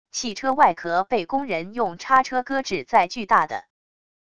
汽车外壳被工人用叉车搁置在巨大的wav音频